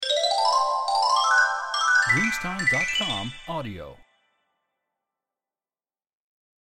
Carillon lungo di scintillio di aumento